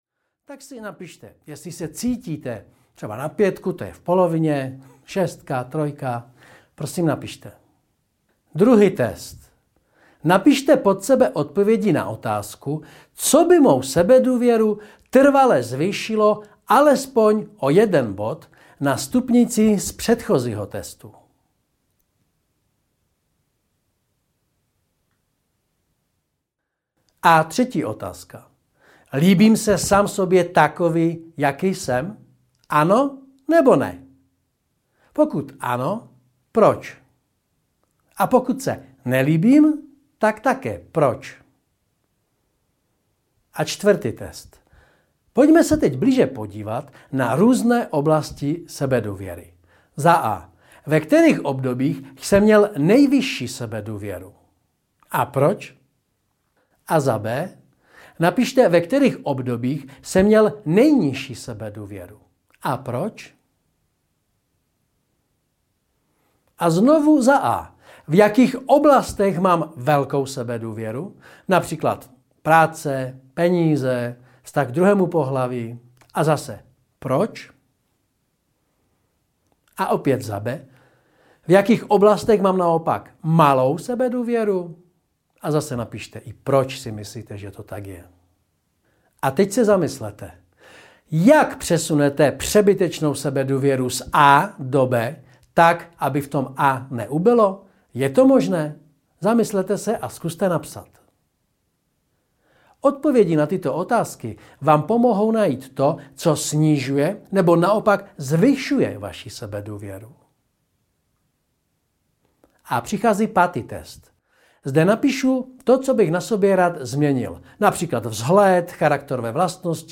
Jak najít sebevědomí audiokniha
Ukázka z knihy